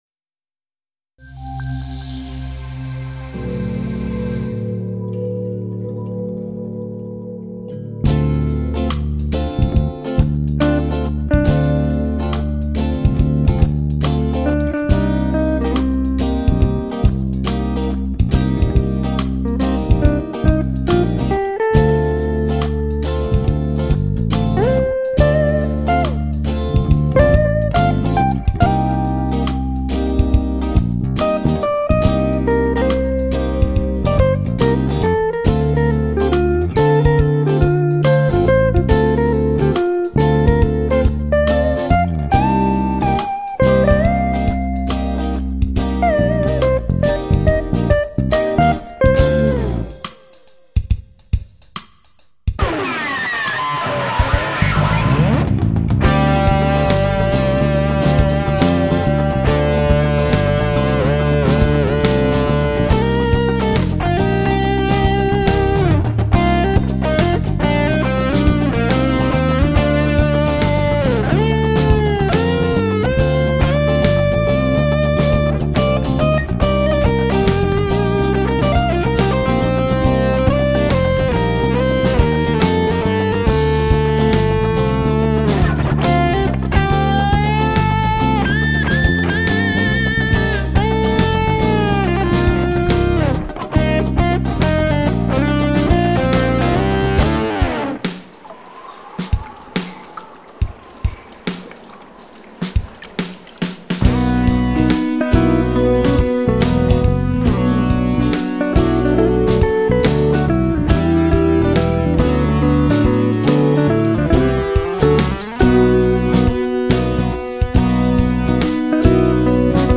Fusion, Instrumental